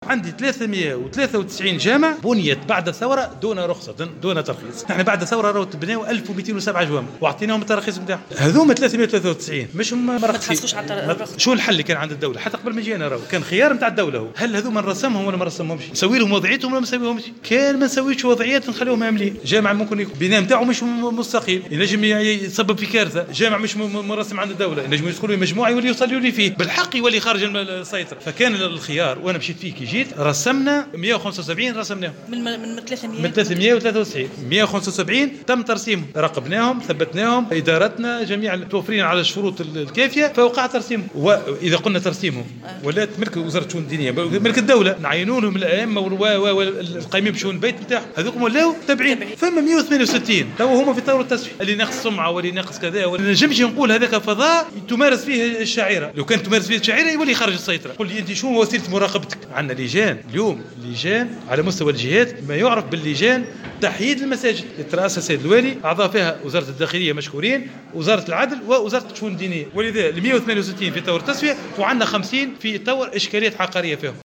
وأوضح الوزير في تصريح لمراسل "الجوهرة اف أم" على هامش ندوة علمية تنظمها وزارة الشؤون الدينية يومي السبت والأحد بالقيروان، بأن 393 مسجدا شيّدت بعد الثورة، دون ترخيص، تم تسوية وضعية 175 منها وترسيمها بوزارة الشؤون الدينية، ملاحظا أنه مازال 162 مسجدا في طور التسوية و50 تستوجب تواجه اشكاليات عقارية.